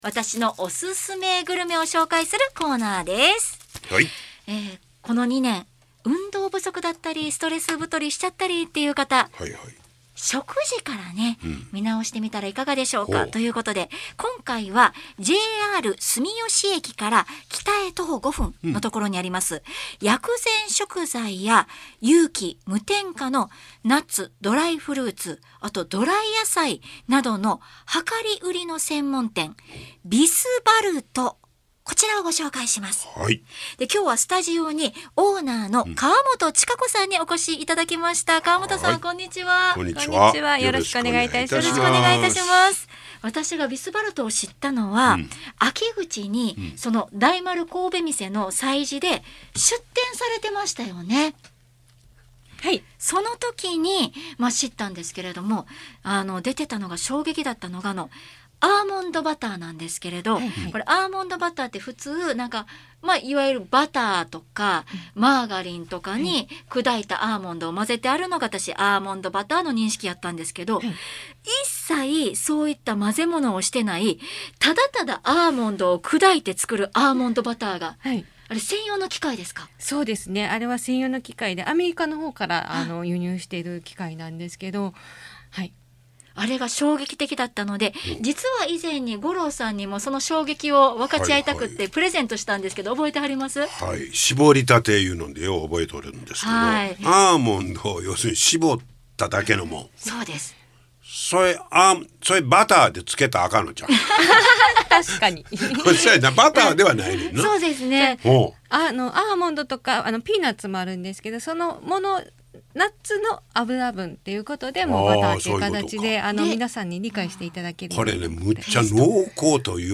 【放送音声】ラジオ関西